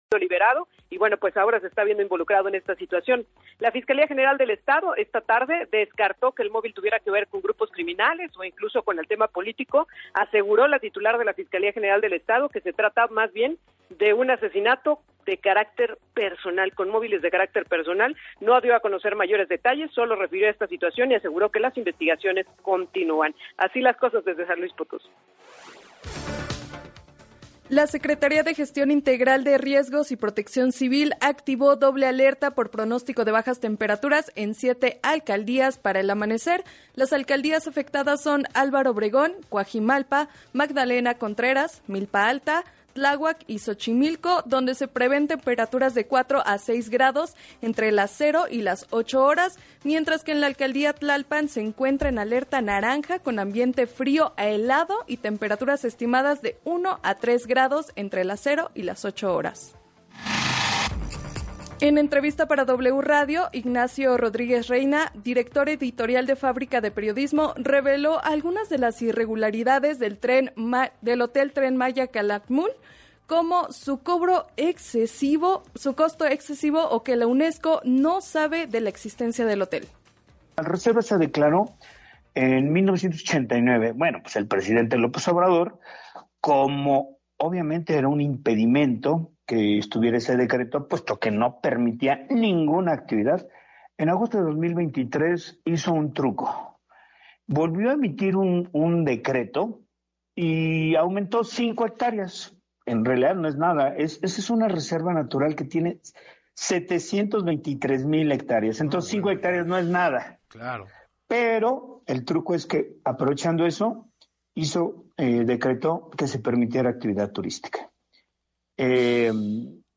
explicó el periodista en entrevista para Así Las Cosas